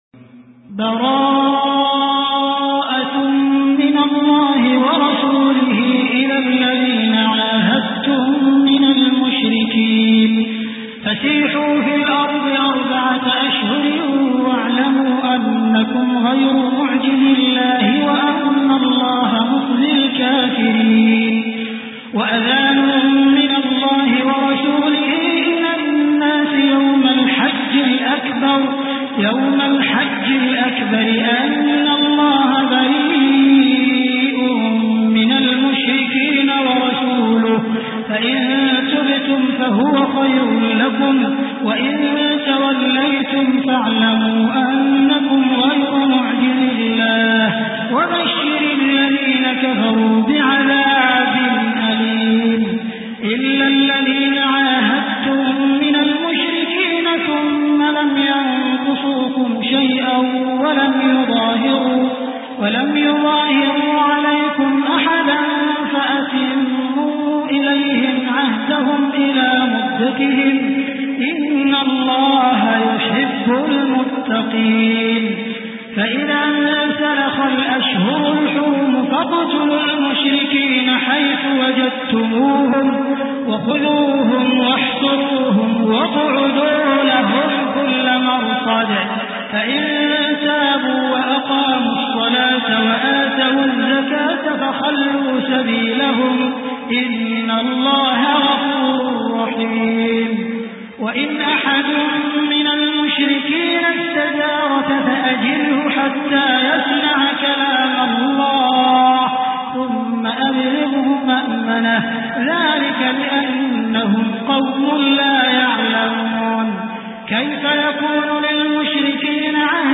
Surah Taubah Beautiful Recitation MP3 Download By Abdul Rahman Al Sudais in best audio quality.